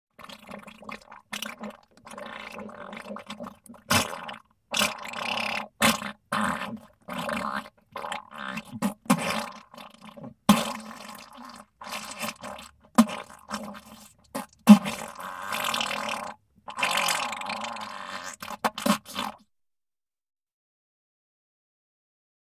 Diarrhea: Funny Wet Sounds.